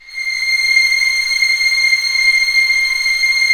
Index of /90_sSampleCDs/Roland L-CD702/VOL-1/STR_Vlns 7 Orch/STR_Vls7 f slo